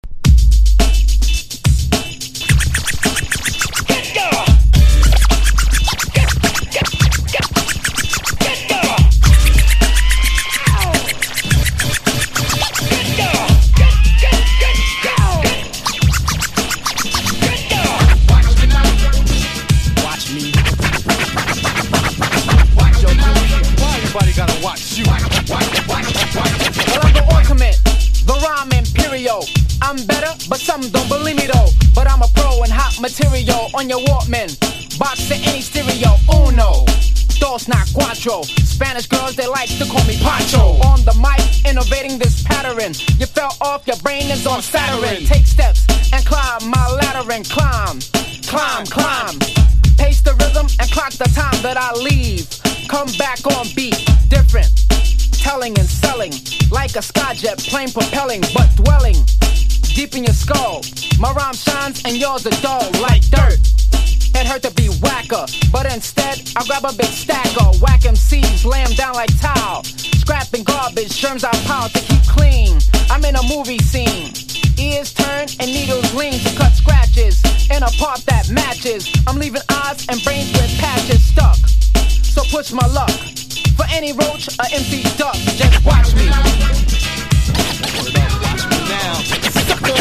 VOCAL VERSION